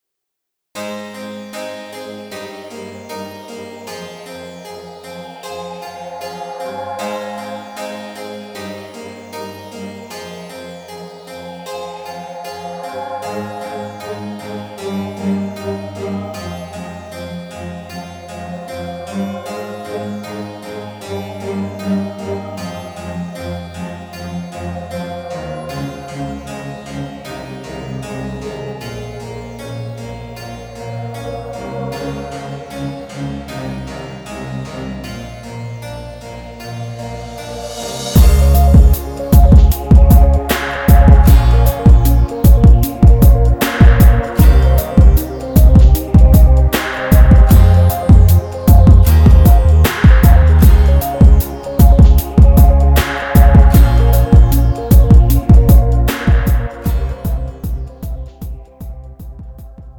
음정 (-1키)
장르 pop 구분 Pro MR